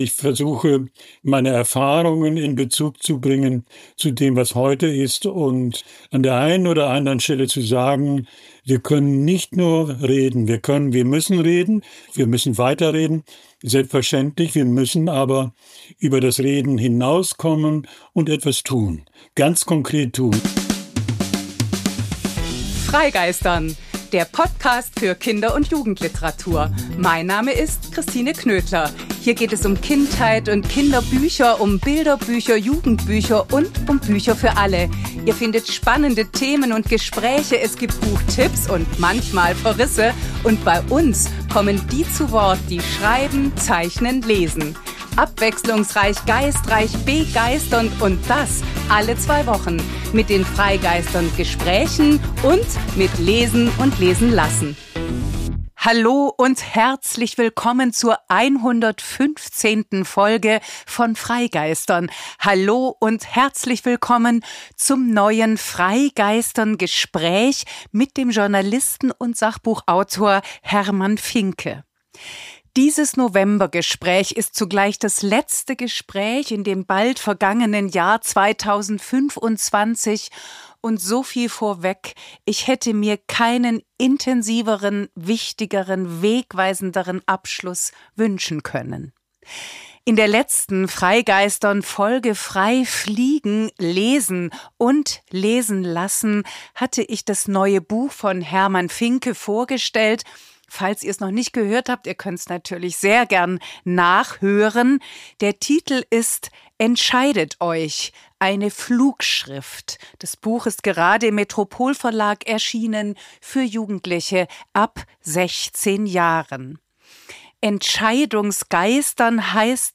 Und so ist die 115. freigeistern!-Folge ein Gespräch über Widerspruch und Widerstand, über rechte Radikalisierung, Neonazis, die AfD, und was die Zivilgesellschaft dagegen tun kann, über Erinnerung, Aufklärung, Verantwortung, Vernunft. Denn die Demokratie ist in akuter Gefahr.